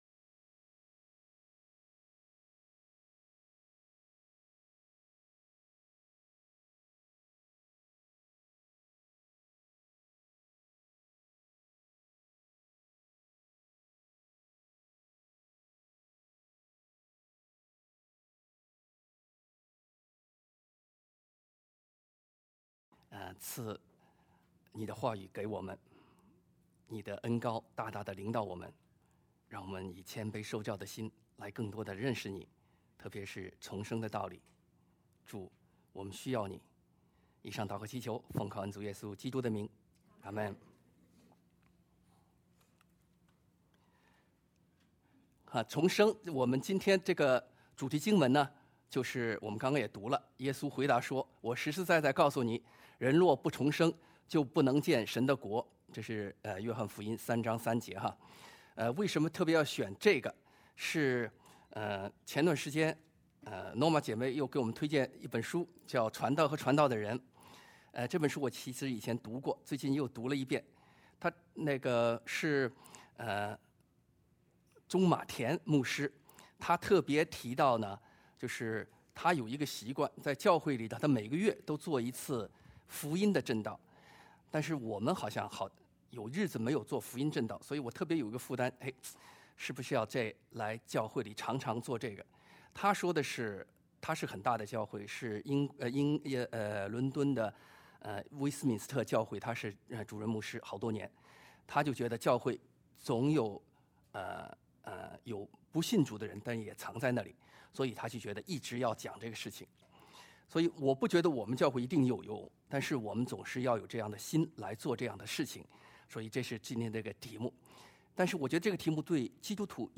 John 3:3 Service Type: 主日证道 Download Files Notes « 聆聽聖靈的聲音 不要怕，赶快去！